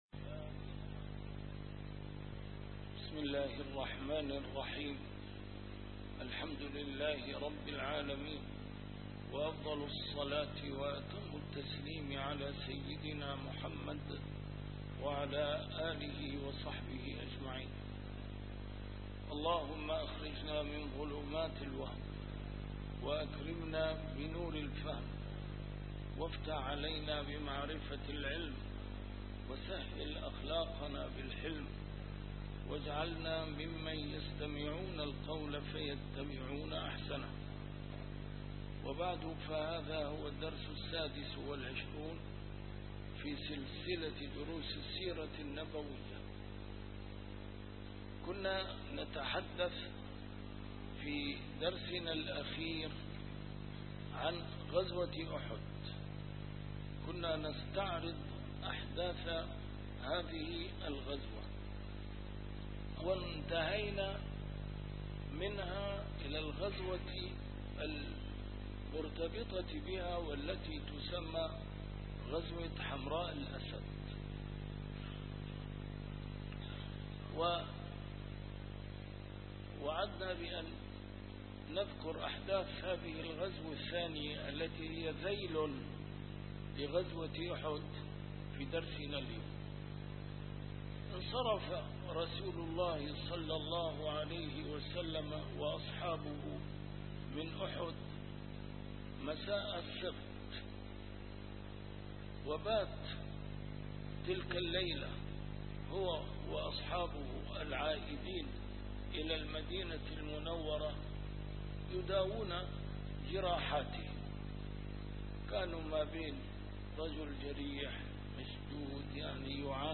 A MARTYR SCHOLAR: IMAM MUHAMMAD SAEED RAMADAN AL-BOUTI - الدروس العلمية - فقه السيرة النبوية - فقه السيرة / الدرس السادس والعشرون : غزوة أحد وحمراء الأسد